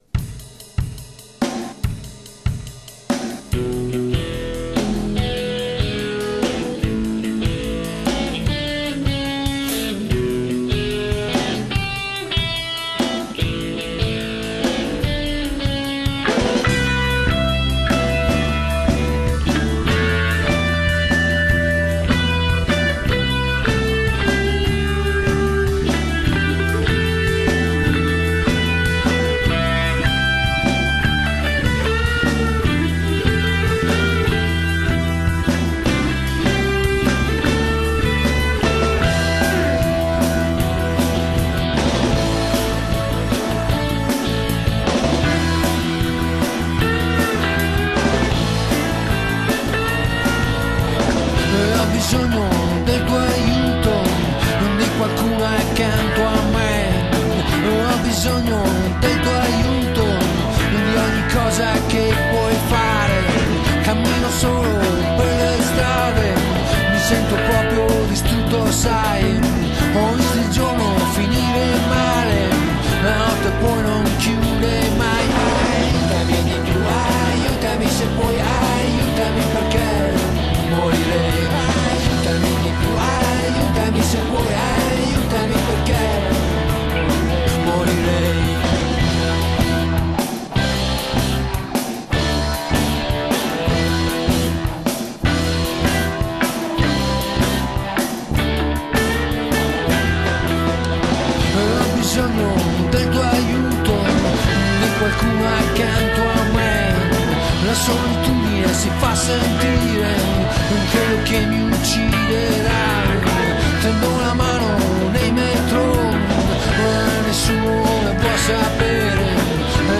Luogo esecuzioneCa Vaina